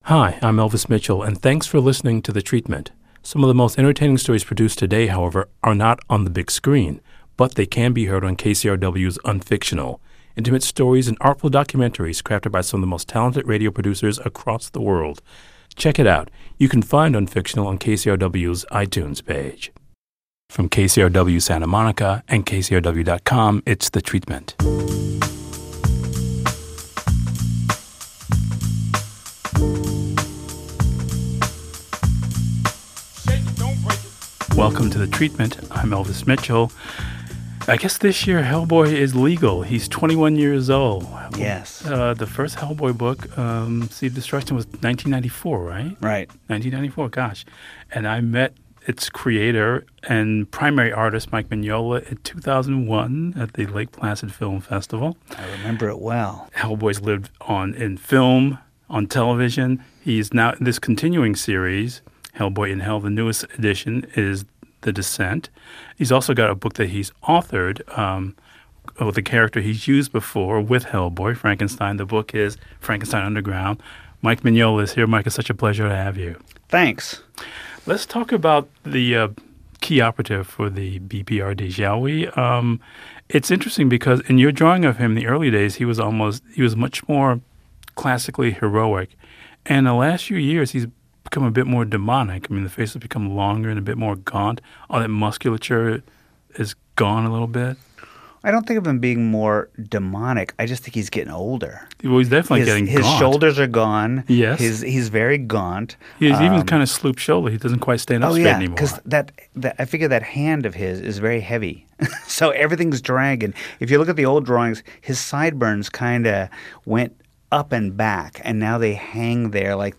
Comic book artist and Hellboy creator Mike Mignola talks about trusting his gut and creating a constant sense of play in his work.